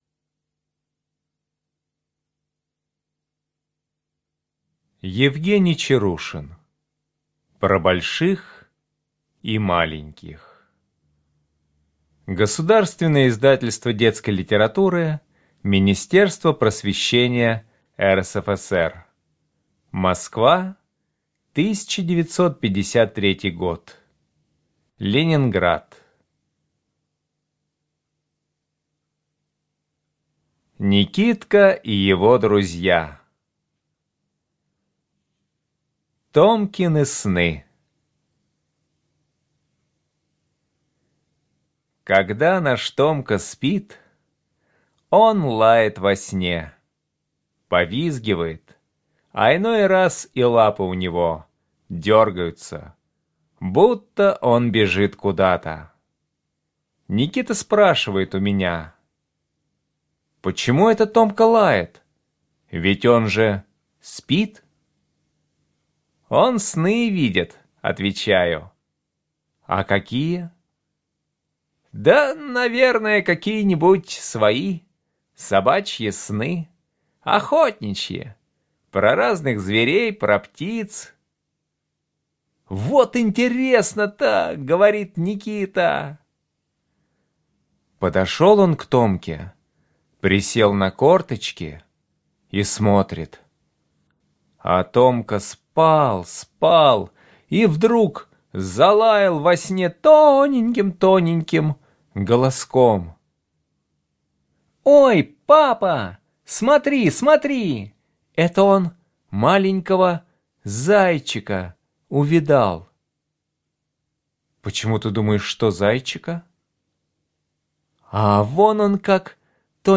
Аудиорассказ «Томкины сны»